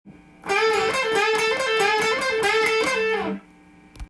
すべてのフレーズがDまたはD7で使えます。
多用する王道ペンタトニックスケールフレーズです。